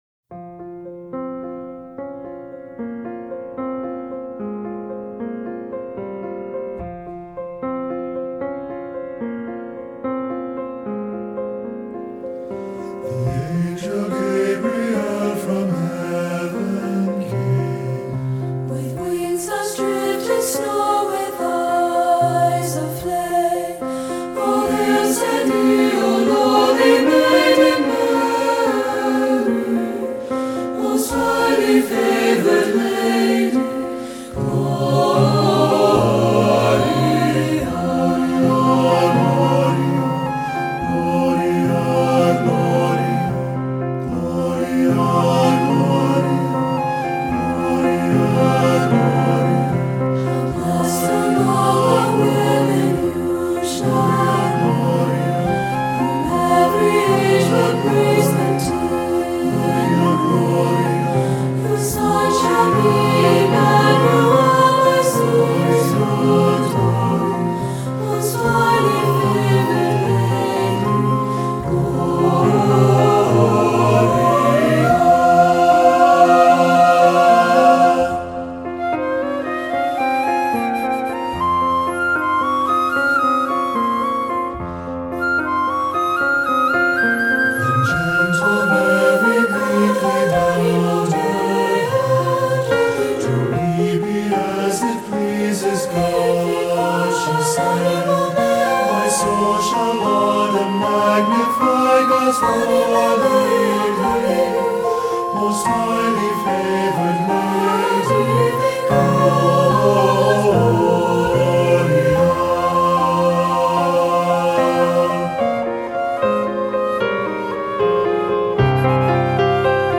Accompaniment:      Piano, Flute
Music Category:      Choral
A traditional melody with a fresh arrangement
Parts of the Magnificat text (in Latin) are interspersed.